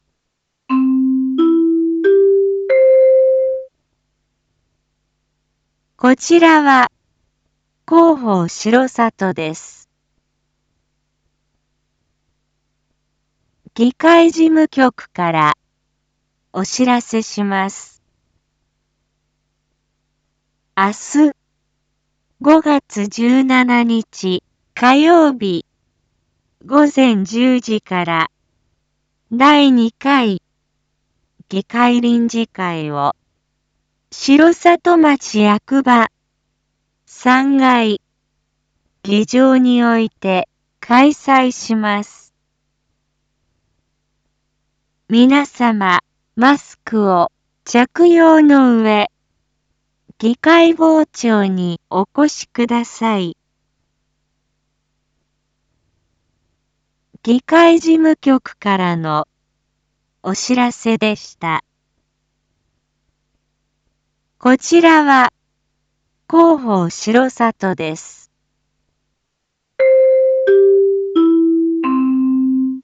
一般放送情報
Back Home 一般放送情報 音声放送 再生 一般放送情報 登録日時：2022-05-16 19:01:14 タイトル：R4.5.16 19時放送分 インフォメーション：こちらは広報しろさとです。